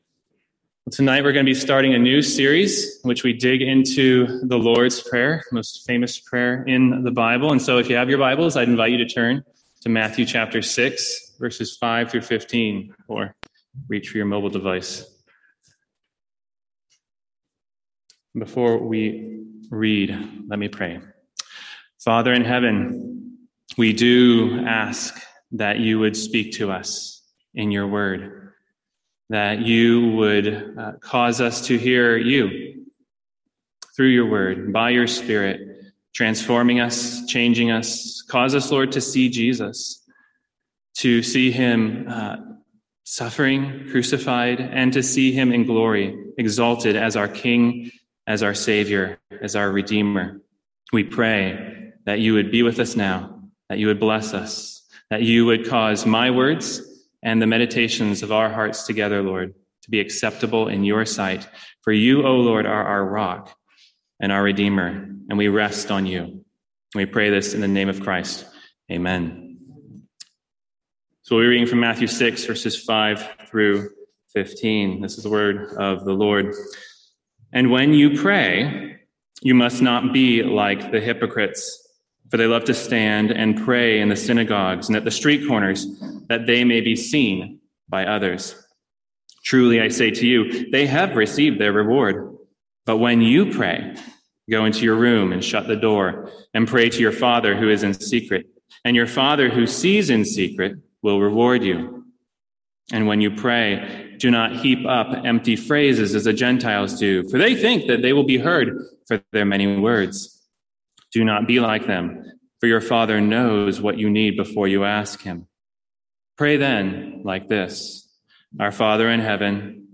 Sermons | St Andrews Free Church
From our evening series in the Lord's Prayer.